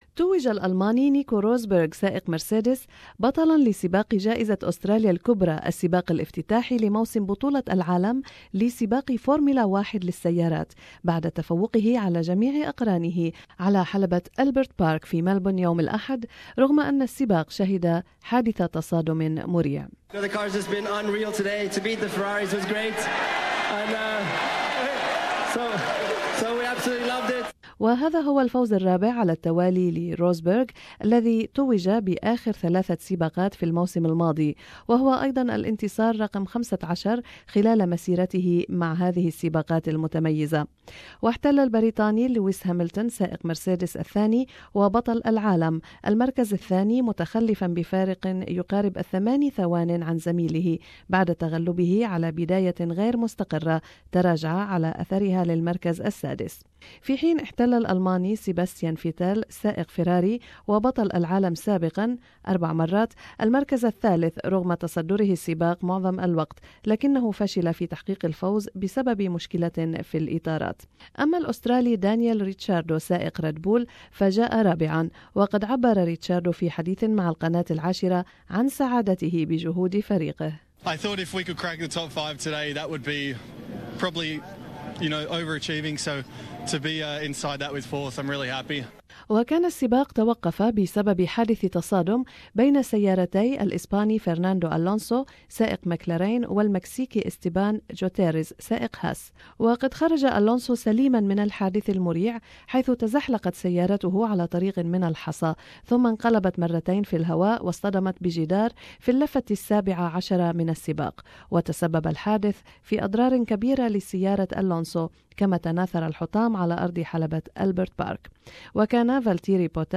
التقرير الرياضي لهذا الأسبوع